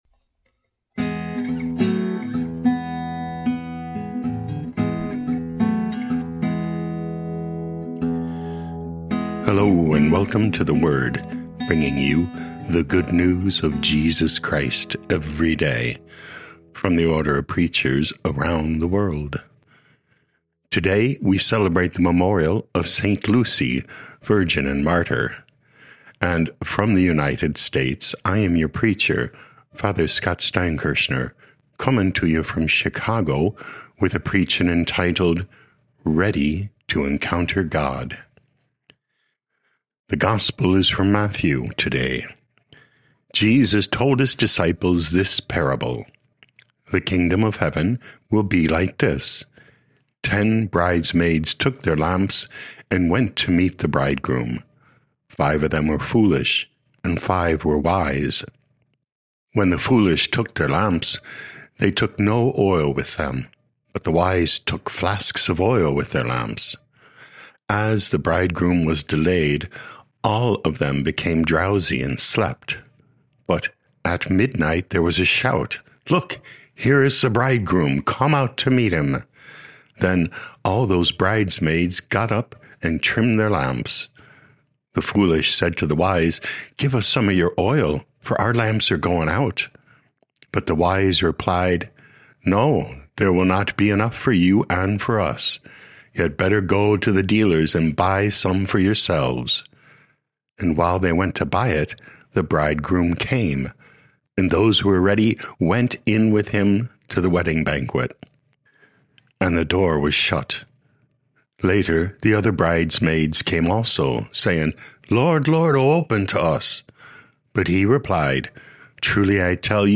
13 Dec 2025 Ready to Encounter God Podcast: Play in new window | Download For 13 December 2025, The Memorial of Saint Lucy, Virgin, Martyr , based on Matthew 25:1-13, sent in from Chicago, Illinois, USA.